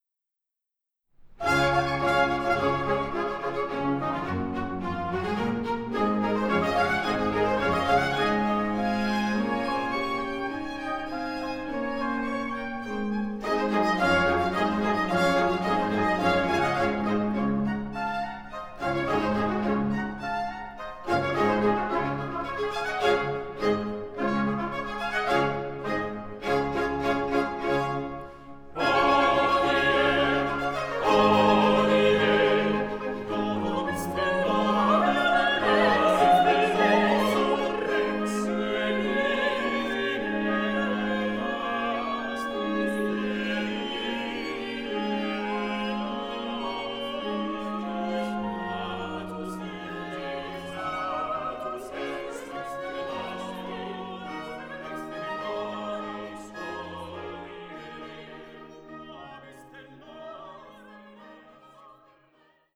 una de las obras de referencia del Barroco musical español.